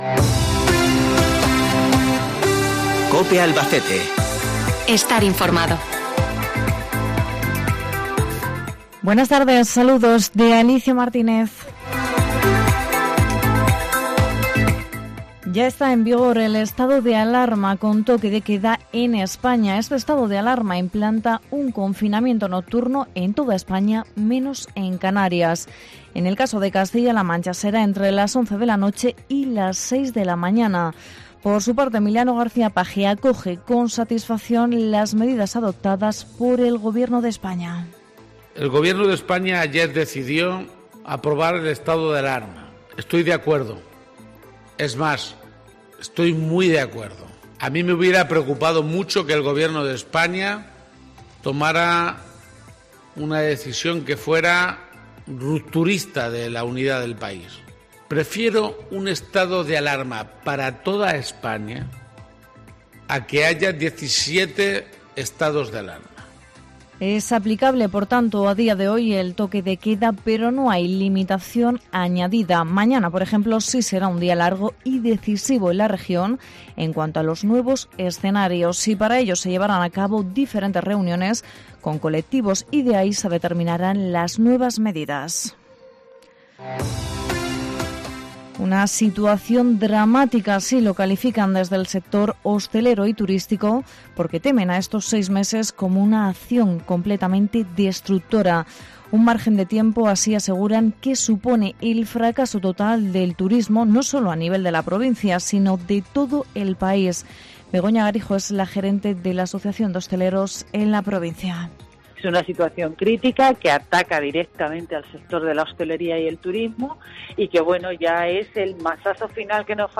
INFORMATIVO LOCAL 26 DE OCTUBRE